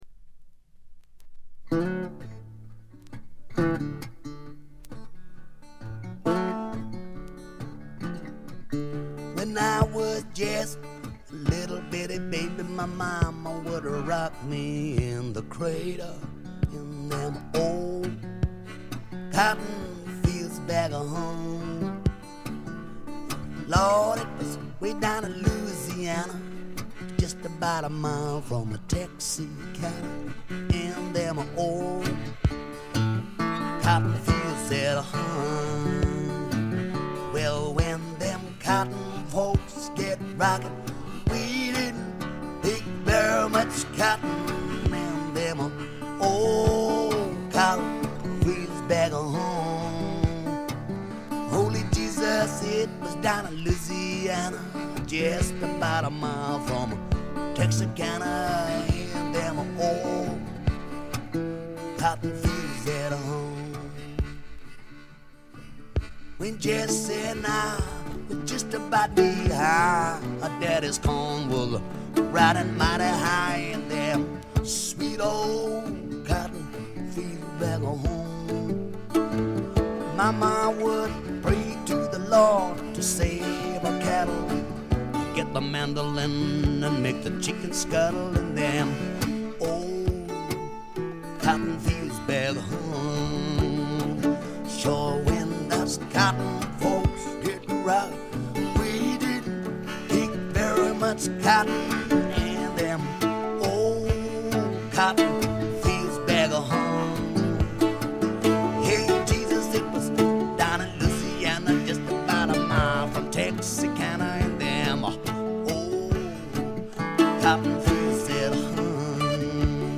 部分試聴ですがほとんどノイズ感無し。
内容は激渋のアコースティック・ブルース。
試聴曲は現品からの取り込み音源です。